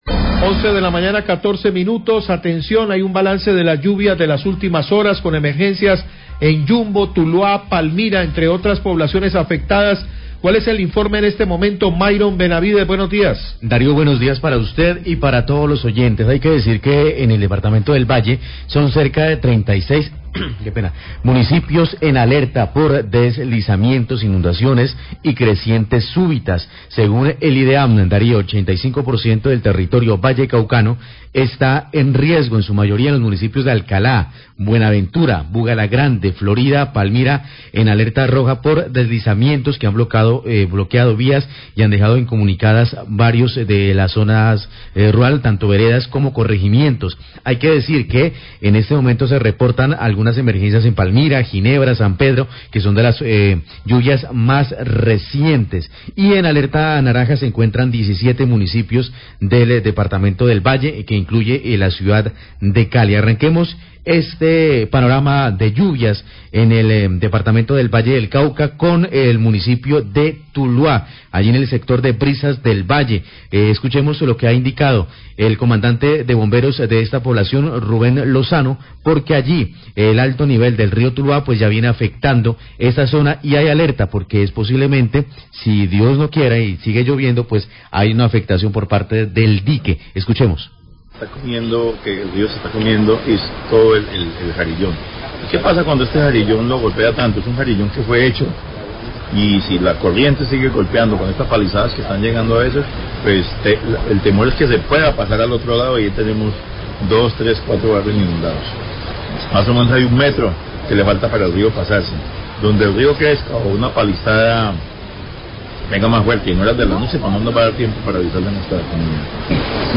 Radio
Inicialmente, autoridades de socorro del Valle presentan un balance de las emergencias en varios municipios debido a las fuertes lluvias que generaron inundaciones. Luego, el Subsecretario de Gestión Riesgo de Cali, Nicolas Suárez, entrega un balance de las afectaciones por el alto nivel de la cuenca ddel río Cauca en el nooriente de la ciudad y las ayudas que se entregaron a las familias afectadas.